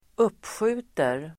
Ladda ner uttalet
Uttal: [²'up:sju:ter]